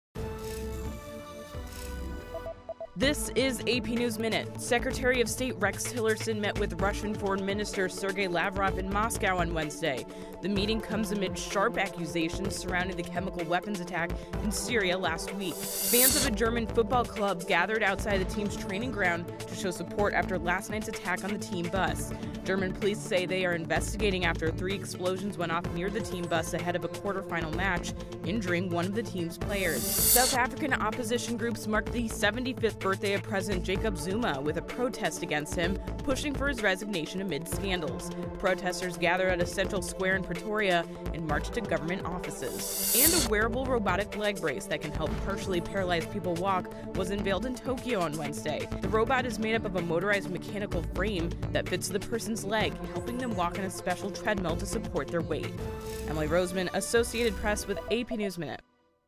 News
美语听力练习素材:美国国务卿与俄罗斯外长会晤